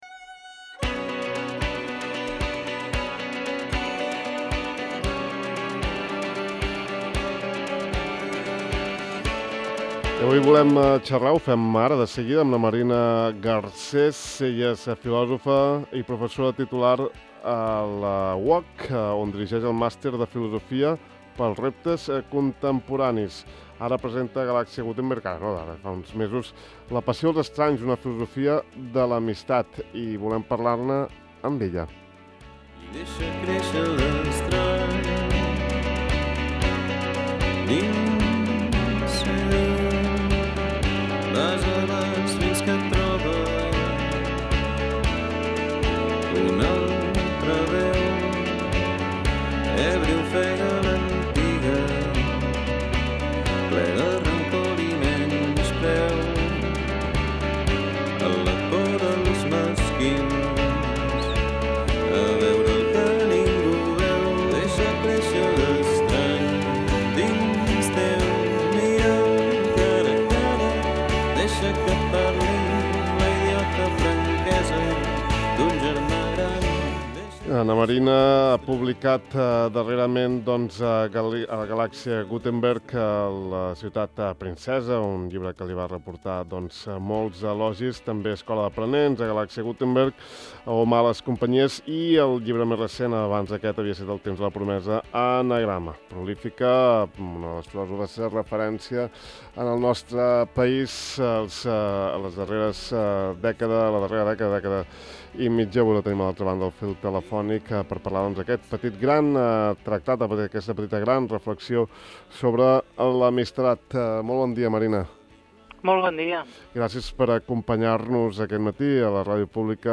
Parlem amb la filòsofa Marina Garcés del seu darrer assaig ‘La passió dels estranys’ (Galàxia Gutenberg), una lúcida reflexió sobre l’amistat, de fet, una filosofia de l’amistat: